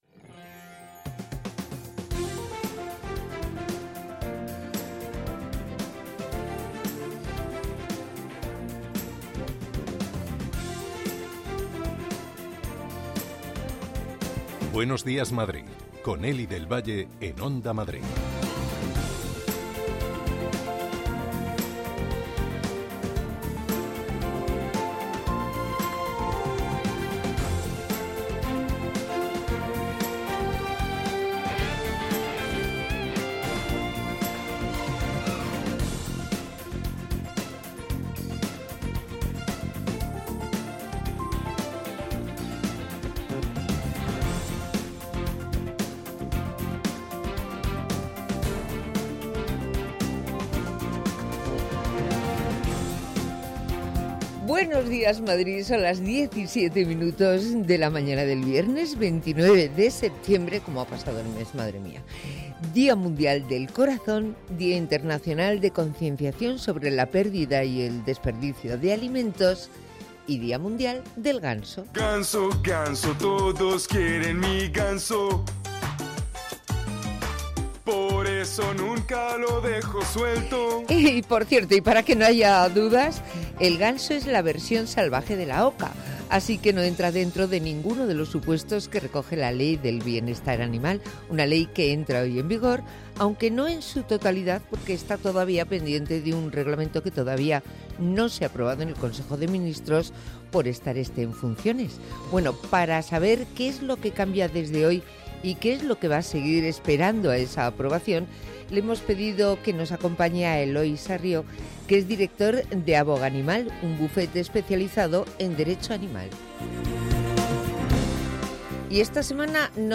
con los oyentes